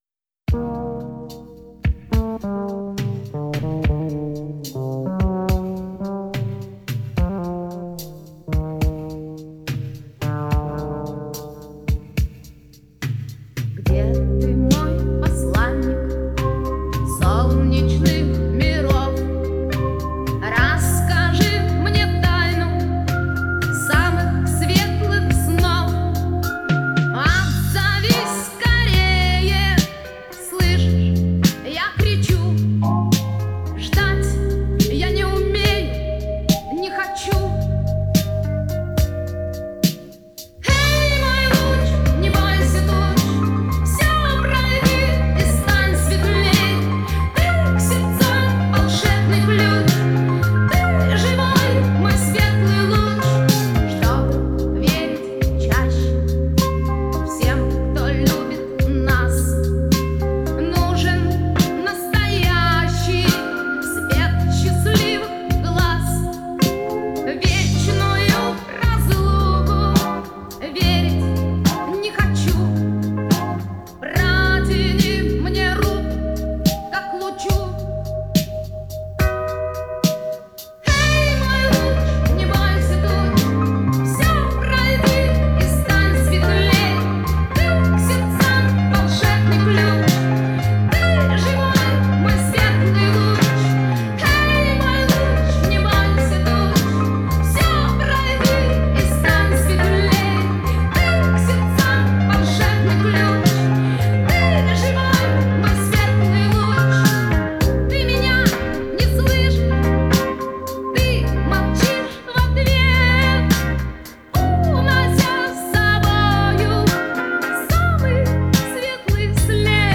Жанр: Rock , Rock & Roll